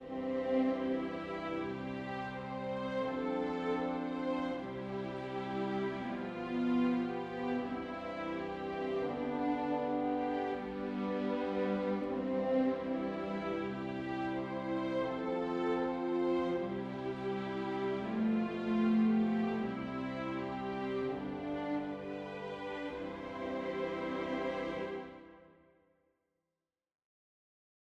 So to make things sound English countryside you just need to play it really slowly and stick some trombones on it.